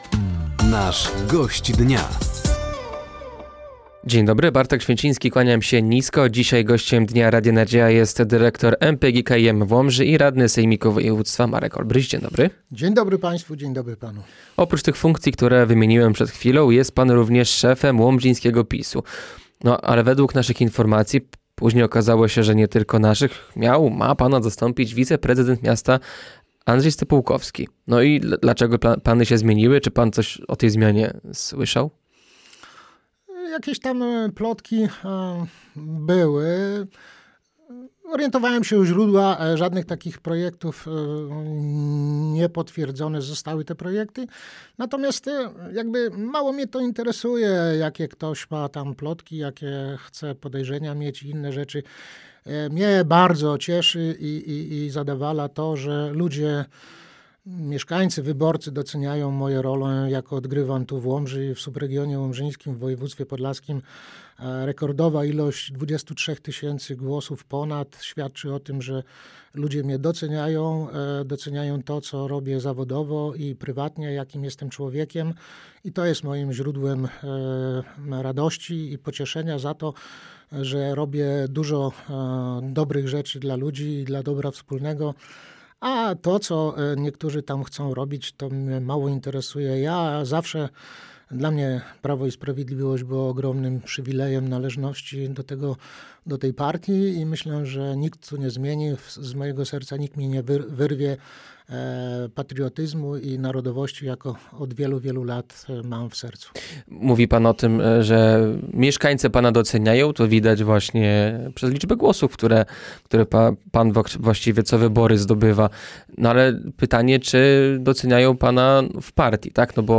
Gościem Dnia Radia Nadzieja był dyrektor MPGKiM w Łomży oraz radny sejmiku województwa Marek Olbryś. Tematem rozmowy była między innymi zmiana szefa struktur PiS w Łomży, ostatnie ulewy, które przeszły przez Łomżę oraz dbanie o czystość w mieście.